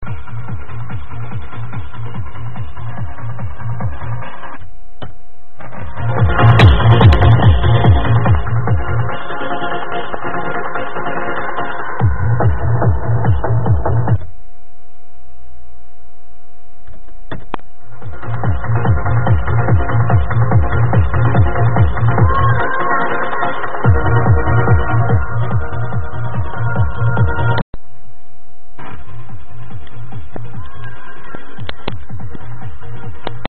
90s trance tune
Recorded with mp3 player, thats why the quality sucks...
Tranceaddict upload makes the quality even worse